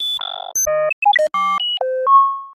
Звуки фантастики